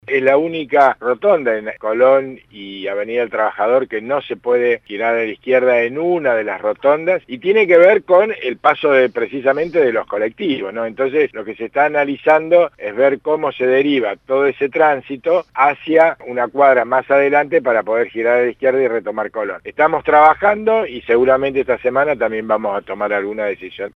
Neuquén.- En diálogo con «Mañanas en Red» por LA RED NEUQUÉN (93.7), el titular de Transporte del municipio, Fernando Palladino, confirmó que esta semana se anunciarán cambios en los giros prohibidos; principalmente en Colón y Avenida del Trabajador.